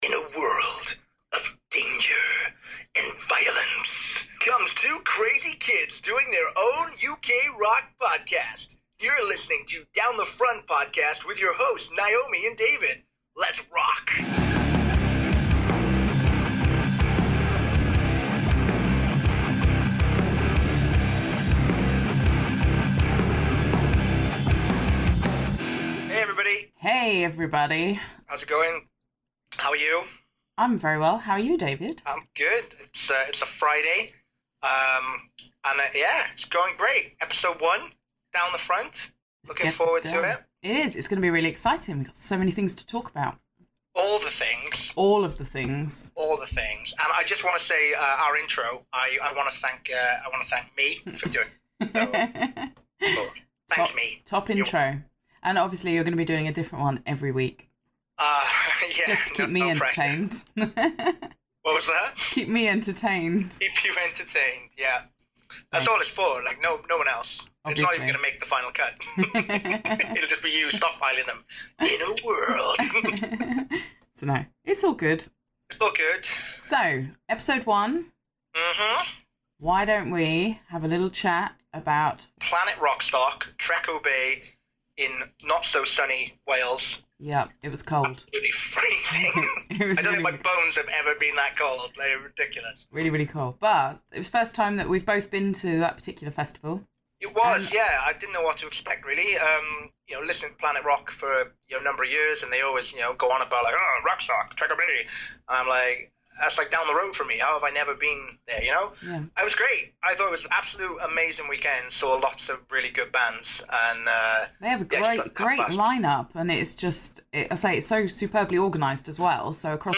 Episode 1 features a review of the recent Planet Rockstock festival and an interview with Californian power trio Idlewar. We talked to them directly after their set!